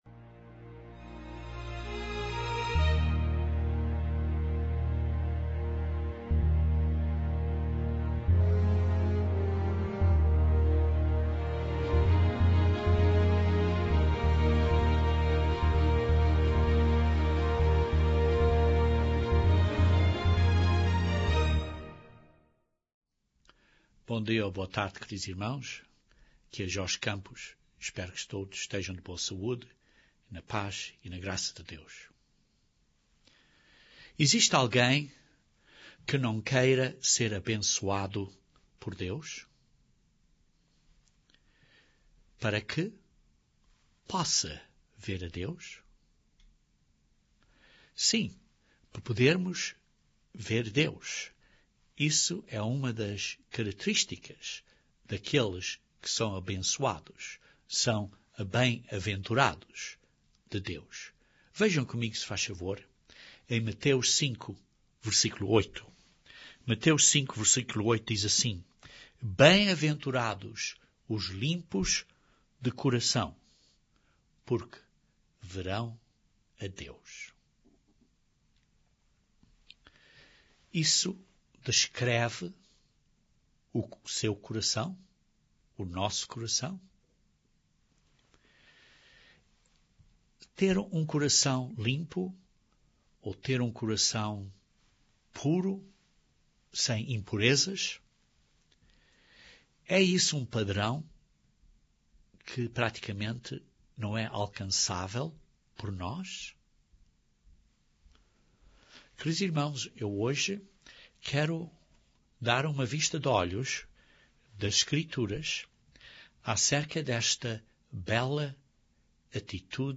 Jesus Cristo disse que aqueles que têm um limpo coração são bem-aventurados pois verão a Deus. O que precisamos de fazer para sermos limpos de coração? Este sermão aborda este assunto.